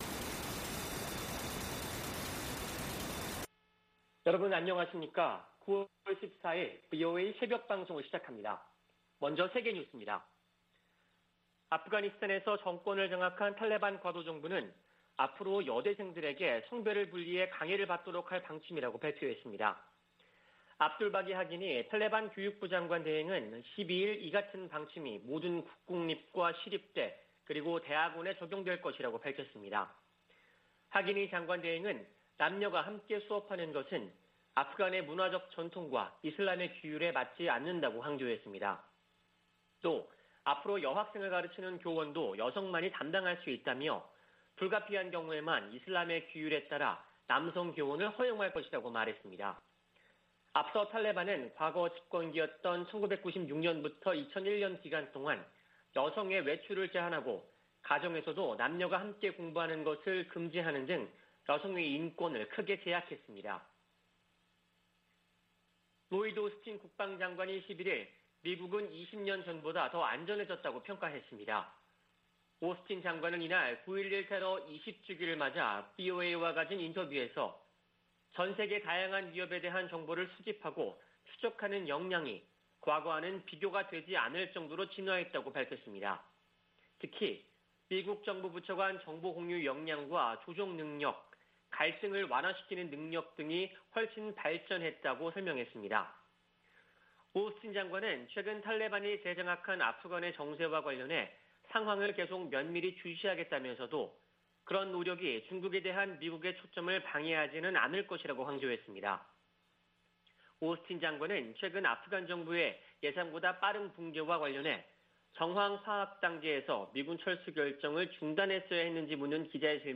VOA 한국어 '출발 뉴스 쇼', 2021년 9월 14일 방송입니다. 북한이 미-한 연합훈련 반발 담화를 낸 지 한 달 만에 신형 장거리 순항미사일을 시험발사했다고 밝혔습니다.